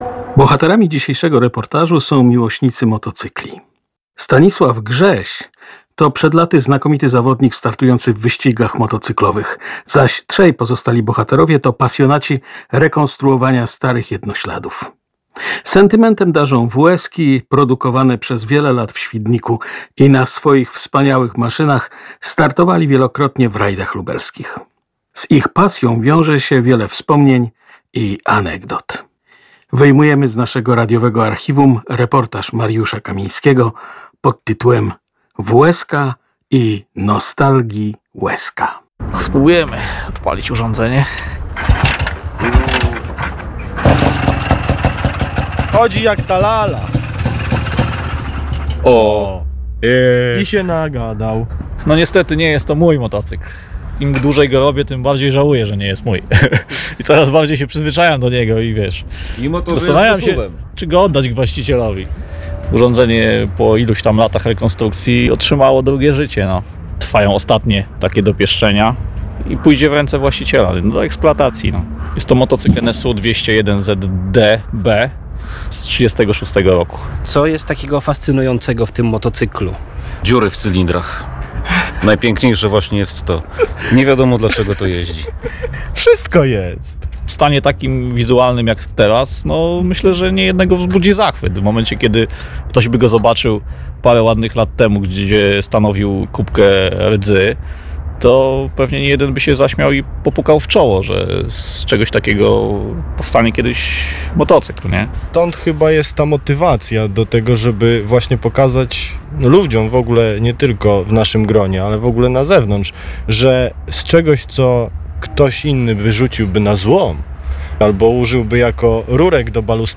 Młodzi opowiadają o swojej pasji, rekonstrukcji starych motocykli, sentymencie, jakim darzą stare dobre WSK-i wyprodukowane w Świdniku, o Rajdzie Lubelskim i związanych z nim anegdotach.